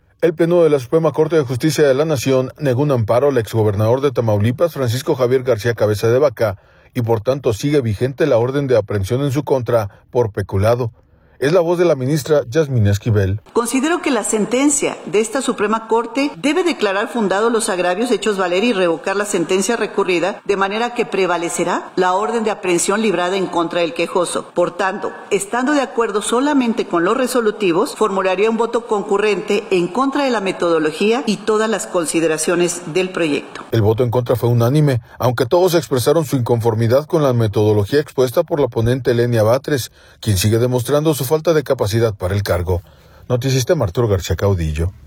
Es la voz de la ministra Yazmín Esquivel